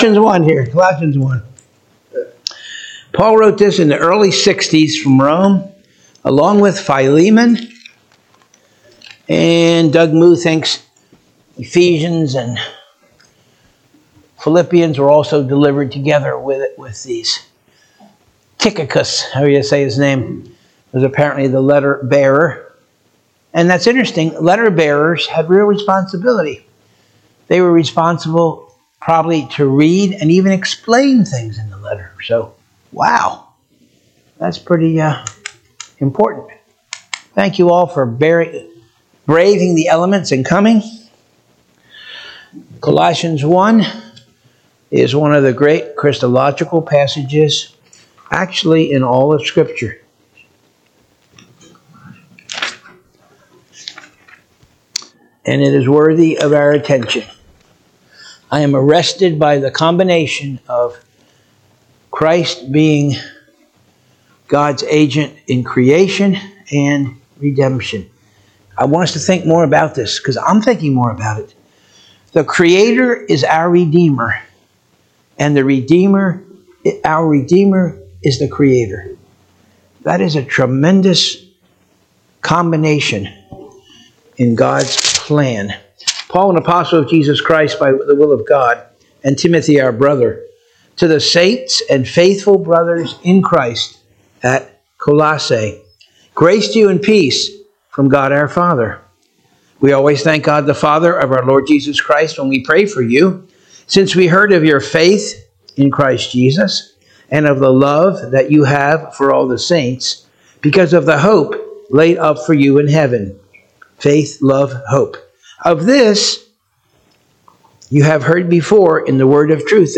Sunday School 2/16/2025 - Covenant of Grace Church